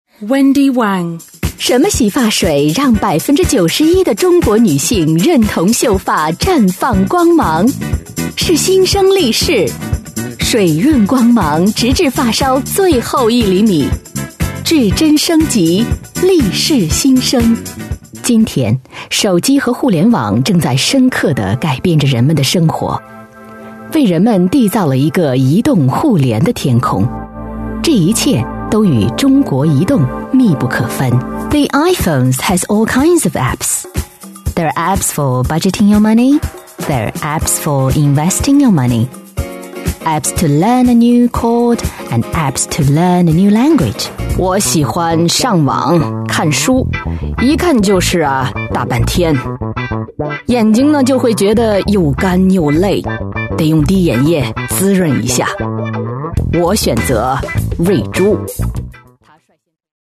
Female / 30s, 40s, 50s / Chinese (Mandarin)
Showreel